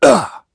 Clause_ice-Vox_Damage_01.wav